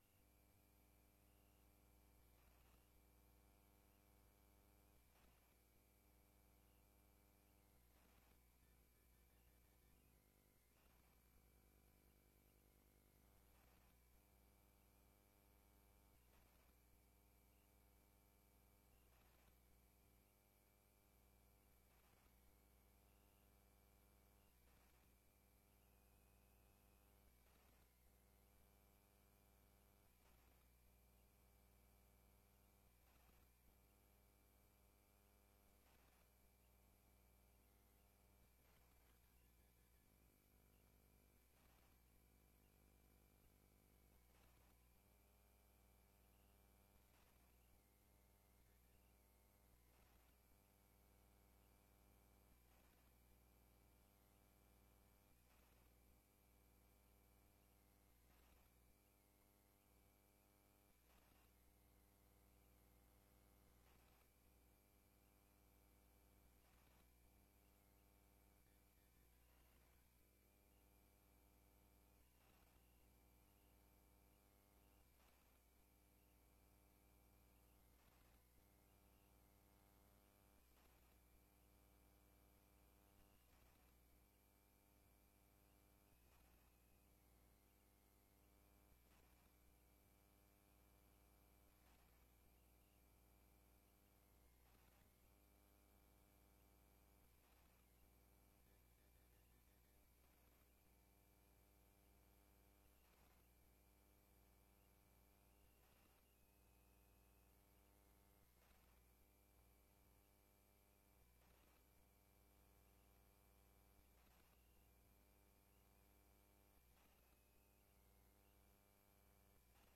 Raadsinformatie avond in De Beeck, Molenweidtje 2, 1862 BC Bergen.